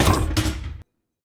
tank-door-open-2.ogg